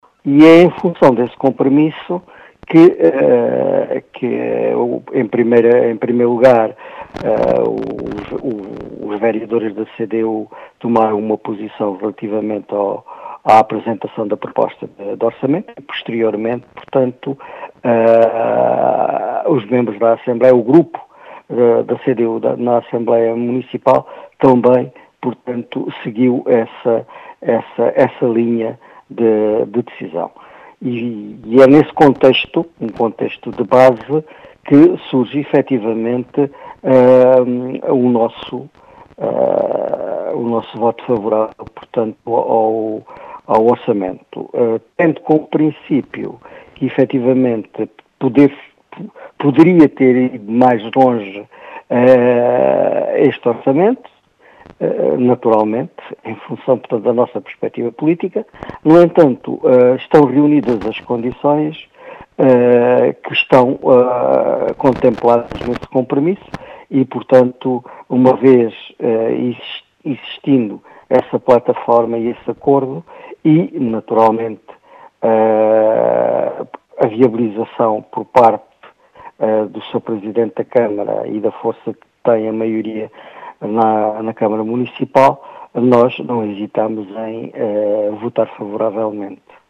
As explicações são de Miguel Quaresma, eleito da CDU na Assembleia Municipal de Beja, que ainda assim afirma que o orçamento “podia ter ido mais longe”.